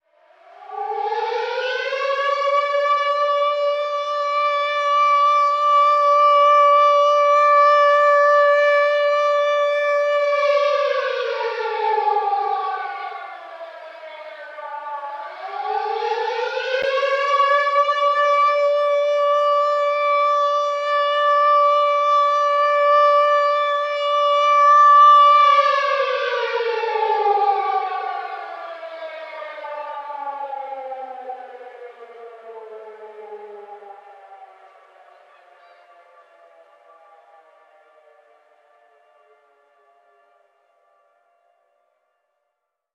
Звуки сирены
Звук сирены воздушной тревоги